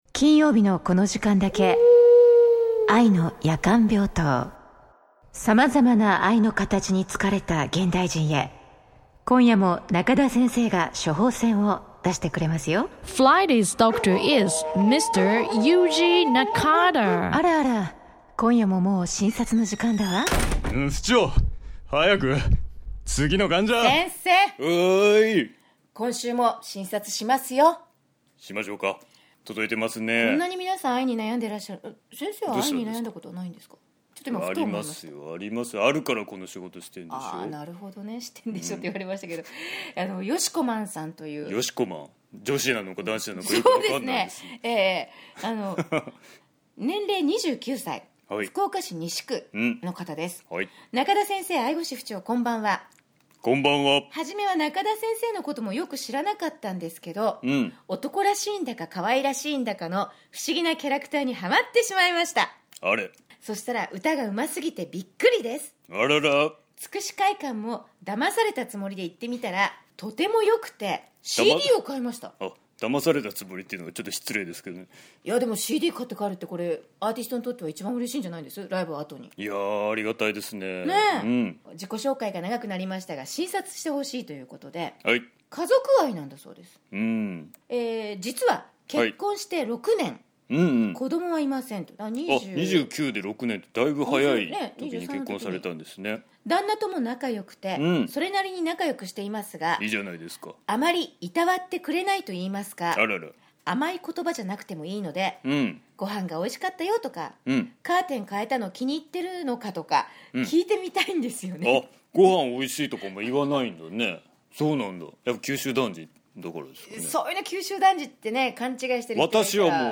いつになく激しいテンションの対象物は・・・・（笑）